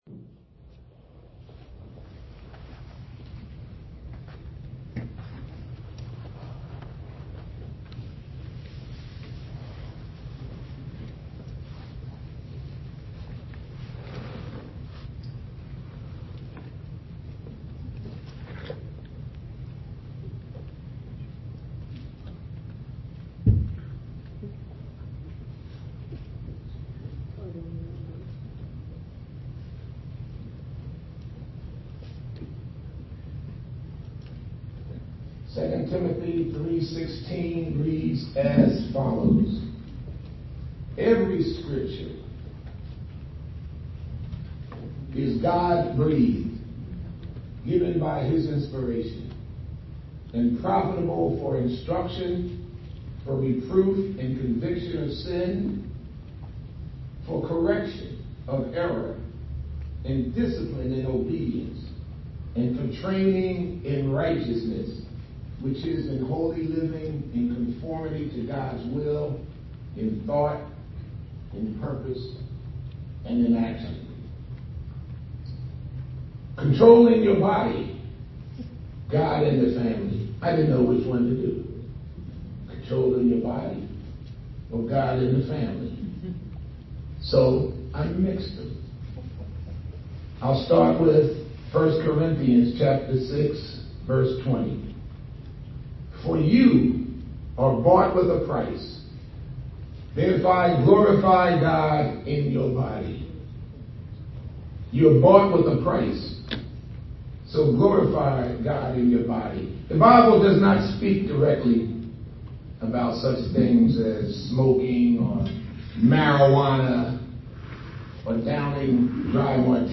Sermons are always best in person!